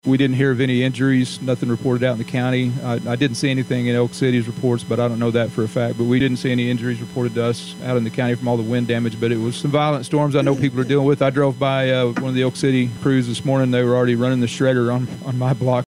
Beckham County Sheriff Derek Manning says fortunately there have been no reports of injuries from the two storms.